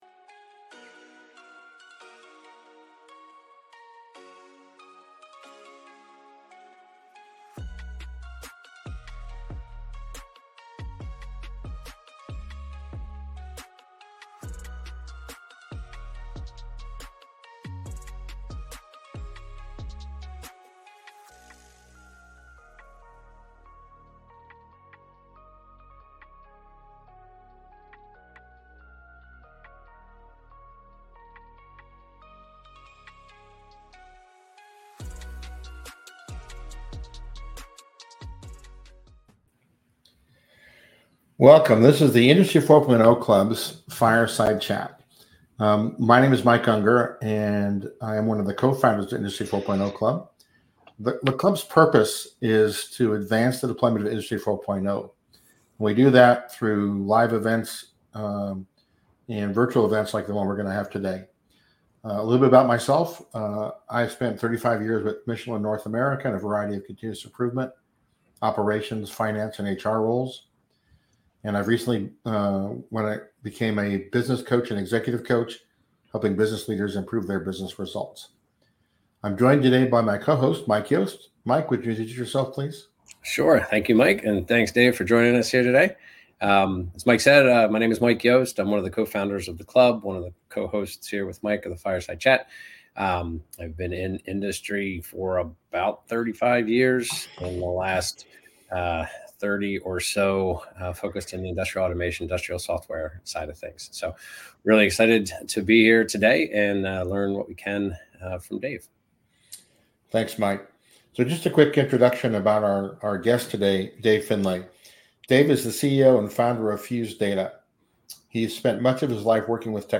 Listen in to the full Fireside Chat here